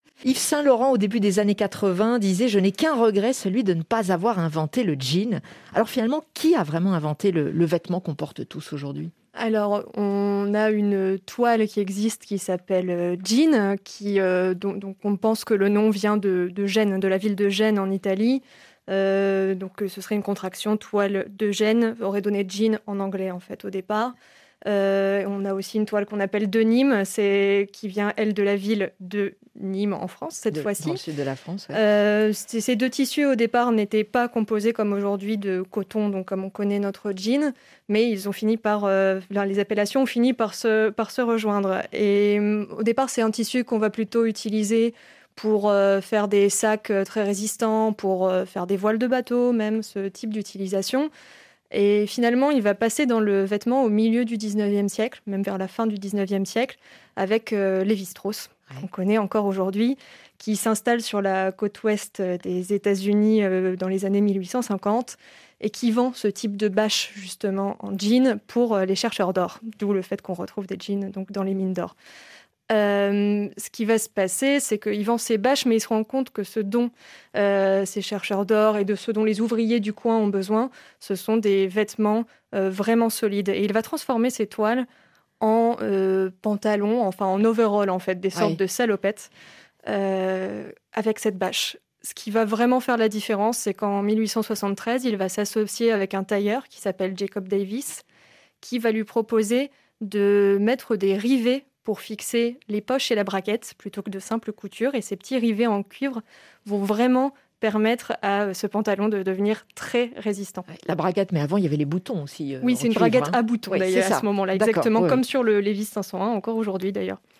RFI – Chronique 8 milliards de voisins du 23 février 2021 « Comment le jean est devenu populaire? »